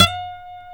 G 5 HAMRNYL.wav